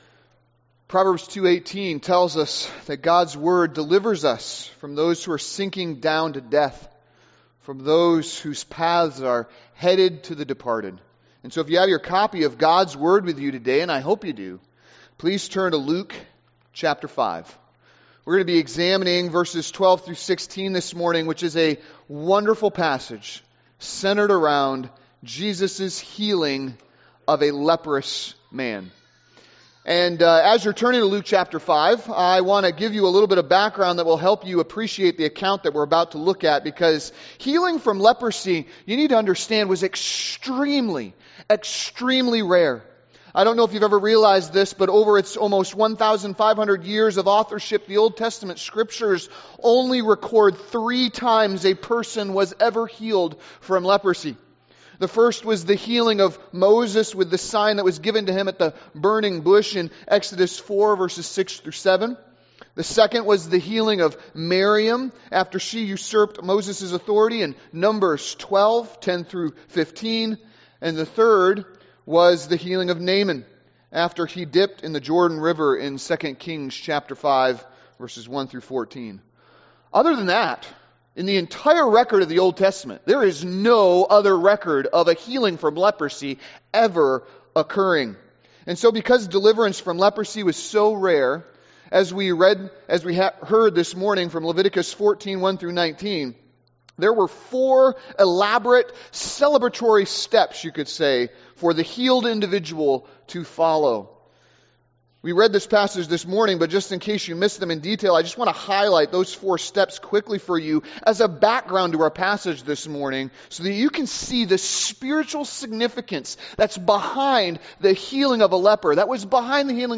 Download Download Reference Luke 5:12-16 Sermon Notes Message Notes Large Print.pdf Luke 5.12-16 - Christ's Divine Cleansing.pdf Bulletin - 08.24.2025.pdf Transcription - Christ's Divine Cleansing.pdf